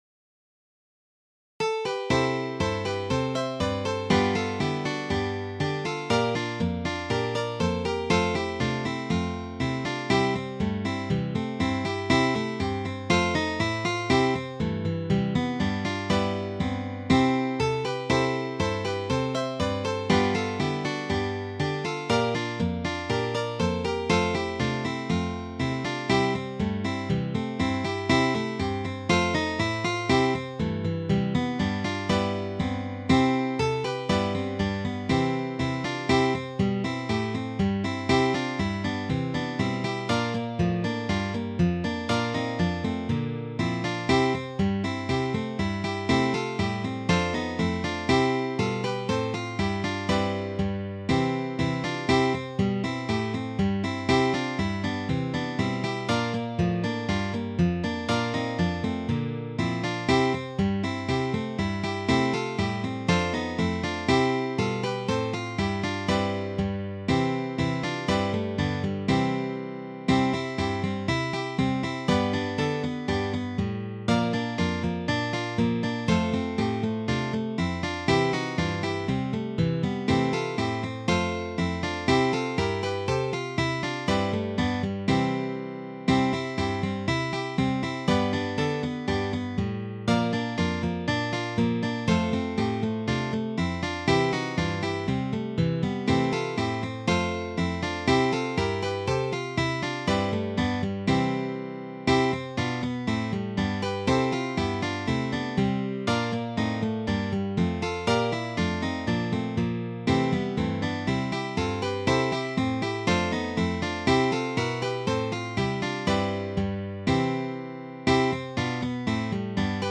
arranged for four guitars